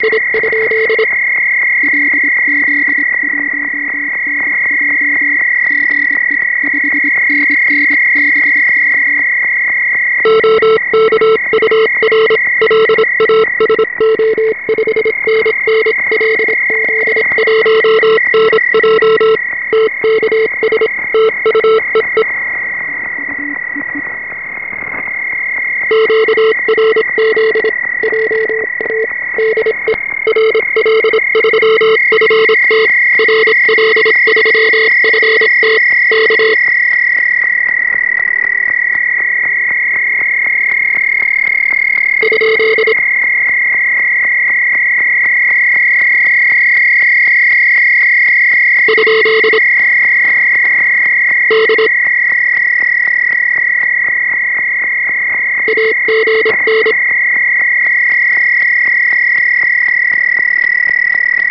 Recordings are done with wide test filters loaded in the AFEDRI8201 chip. Recording location is Espoo Finland and antenna is 80m dipole low in the bushes. MP3 samples are left channel only.
After fir2 we have Hilbert filters with pass band from about 100Hz to 3kHz.
MP3 recording from CW band 3505KHz with some QRM